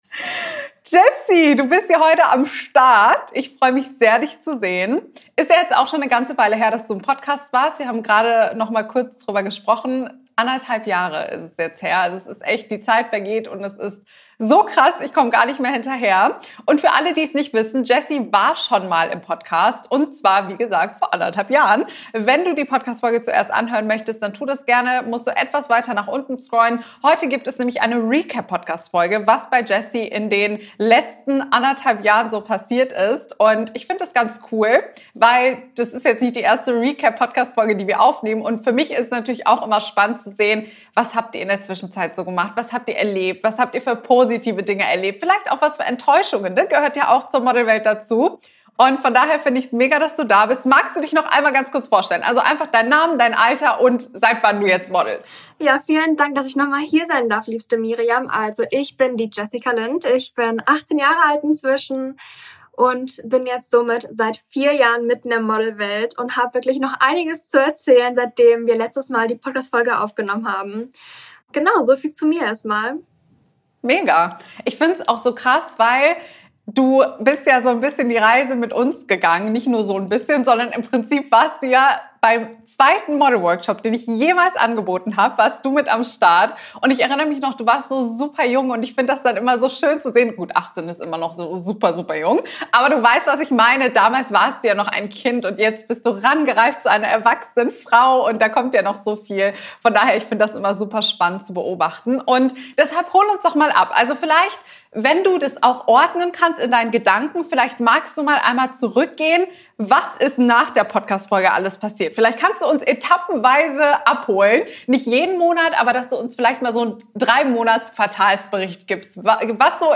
#224: Als Model Grenzen setzten! Interview